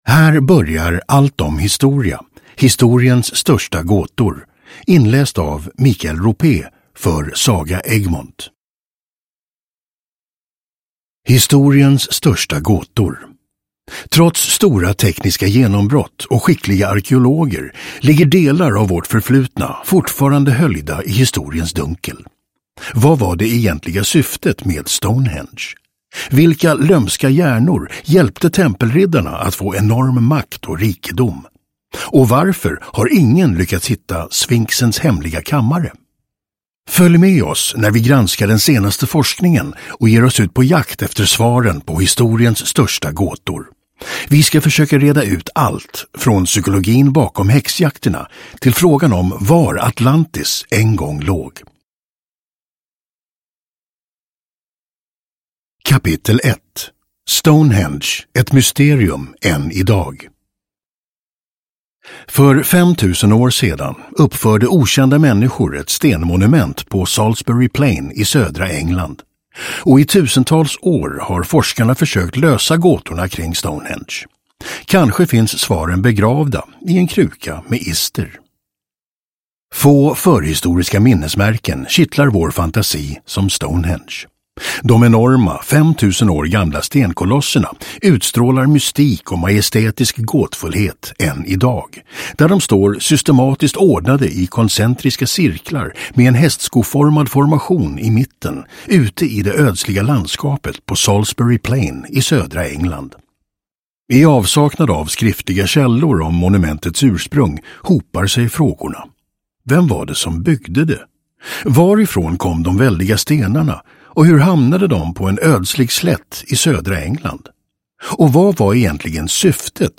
Historiens största gåtor 2 (ljudbok) av Allt om Historia